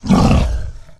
boar_attack_0.ogg